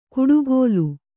♪ kuḍugōlu